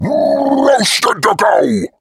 Kr_voice_kratoa_taunt01.mp3